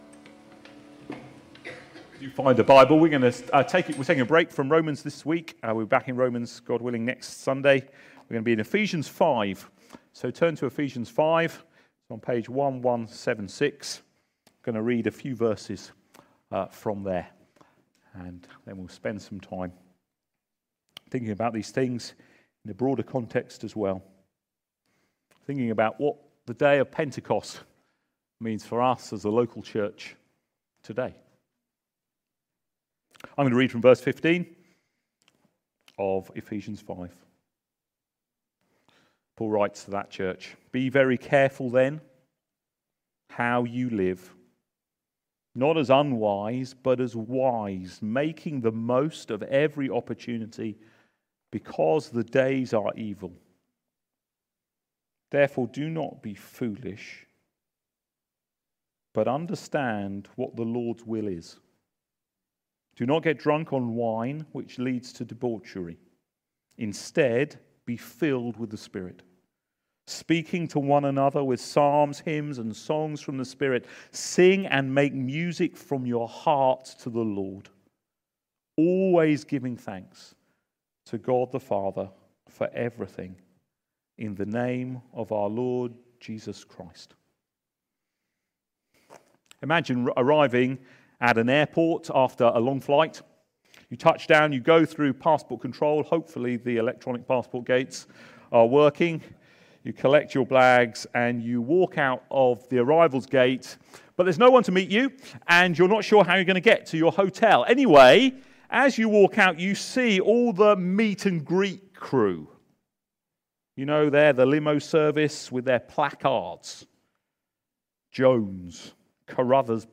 LABC Sermons